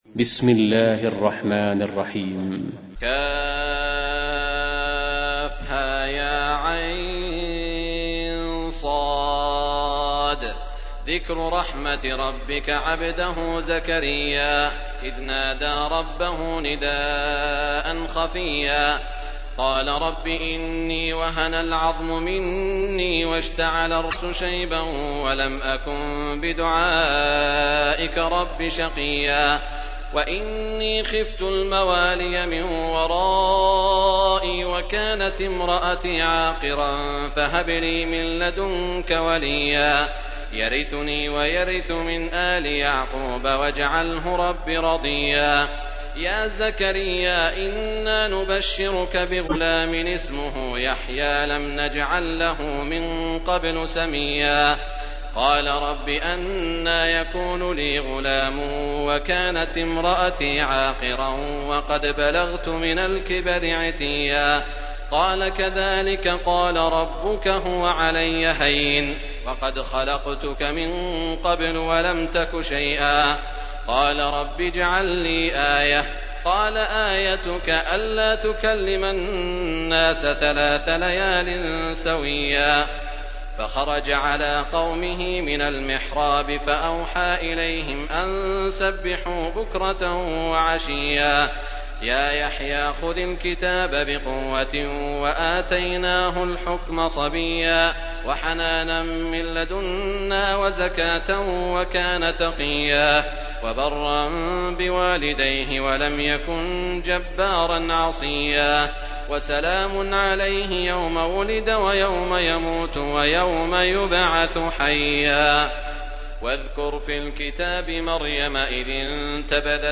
تحميل : 19. سورة مريم / القارئ سعود الشريم / القرآن الكريم / موقع يا حسين